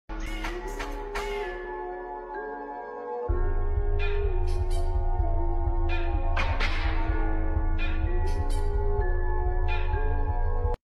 *Whip Sounds* Sound Effects Free Download
*Whip sounds* sound effects free download